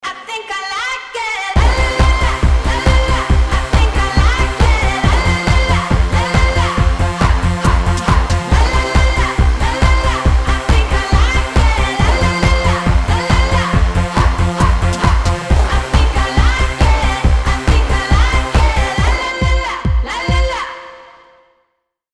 позитивные
веселые
заводные
женский голос
dance
фолк